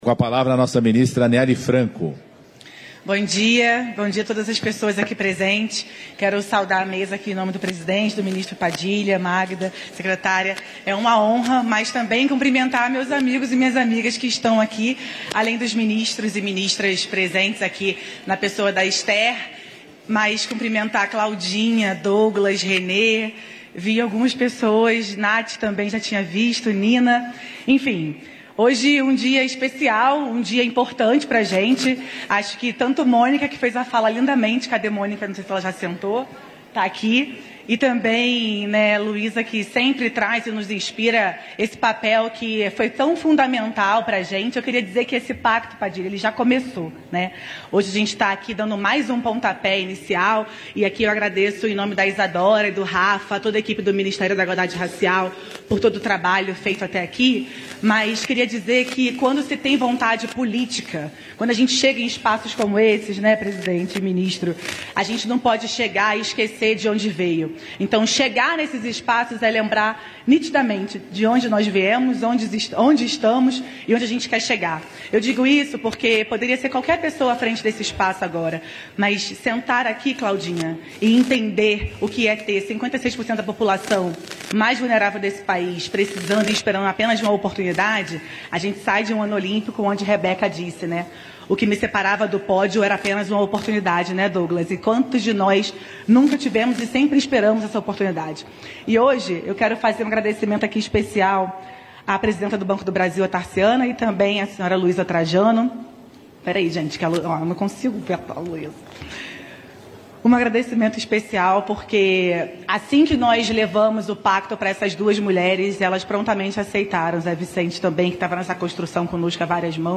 Íntegra da fala da ministra da Igualdade Racial, Anielle Franco, durante a 4ª Reunião Plenária do Conselho de Desenvolvimento Econômico e Social Sustentável (CDES), nesta quinta-feira (12), em Brasília.